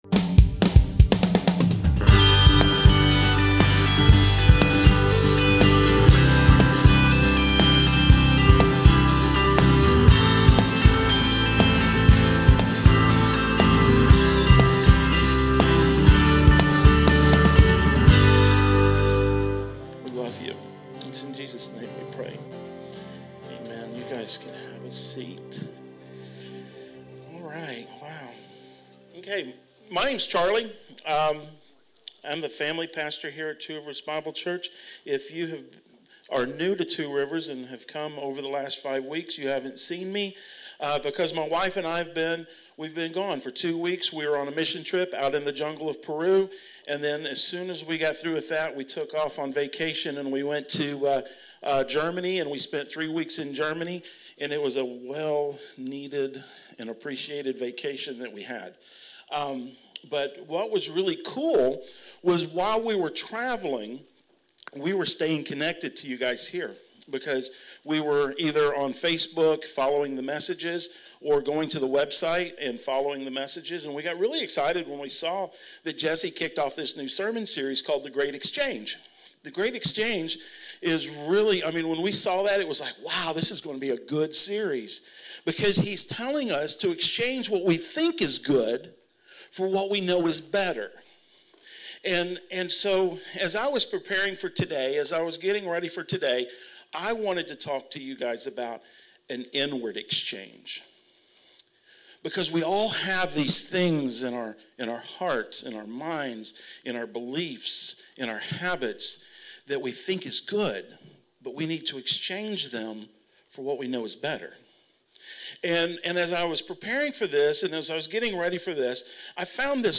Two Rivers Bible Church - Sermons